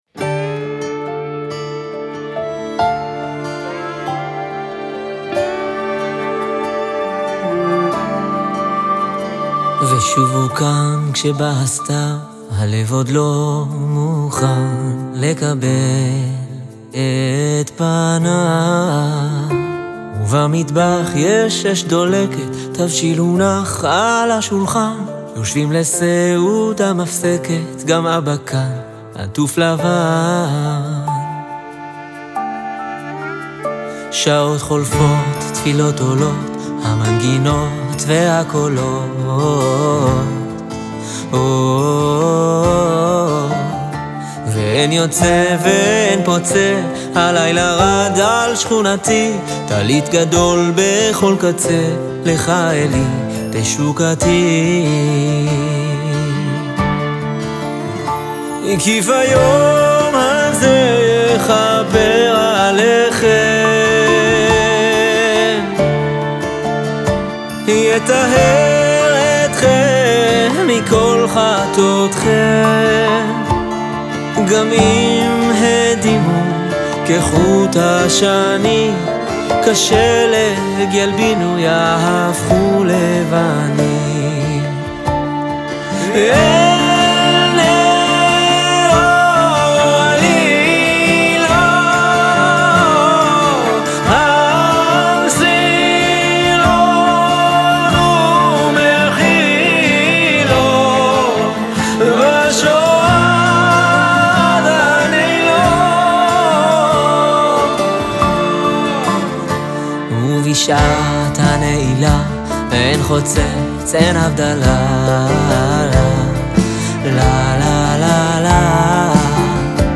לחן קולח ומרעיד כאחד